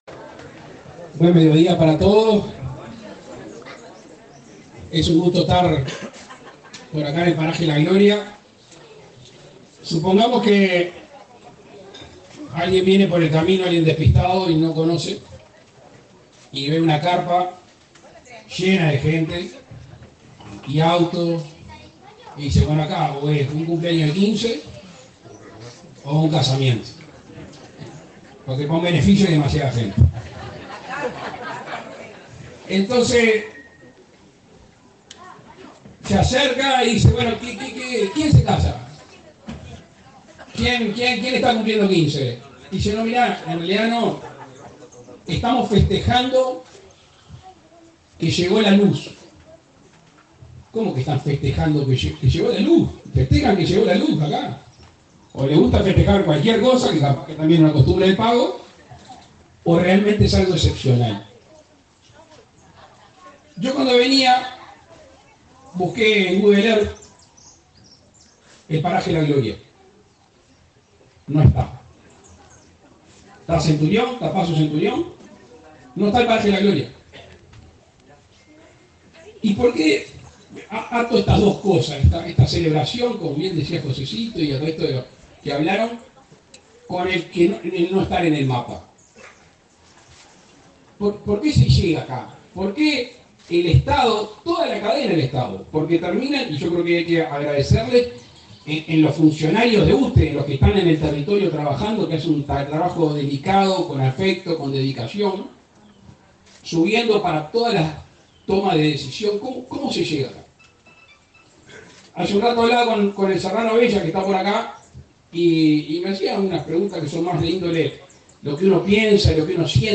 Palabras del presidente Luis Lacalle Pou
Palabras del presidente Luis Lacalle Pou 01/12/2023 Compartir Facebook X Copiar enlace WhatsApp LinkedIn Este viernes 1.°, el presidente Luis Lacalle Pou encabezó el acto de inauguración de obras de electrificación rural en el paraje La Gloria, en el departamento de Cerro Largo.